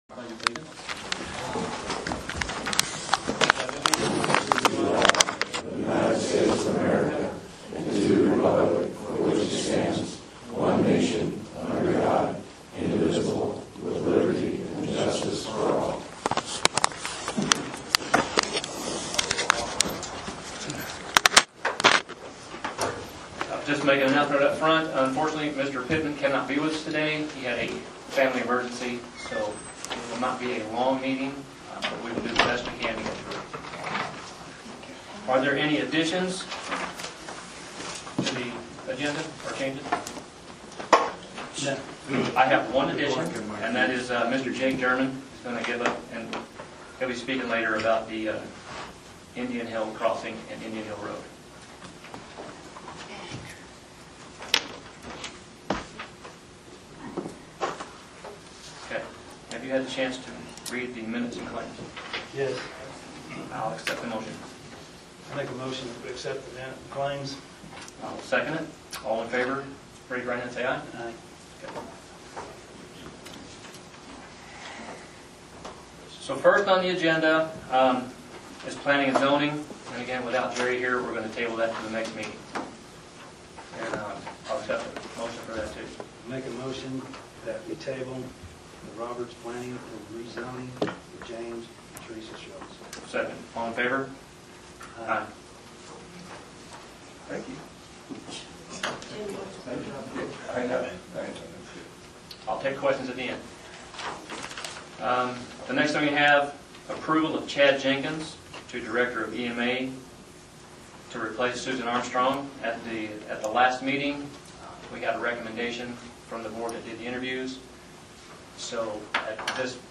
Commissioner Meeting Notes, May 3, 2023. 2:00 p.m.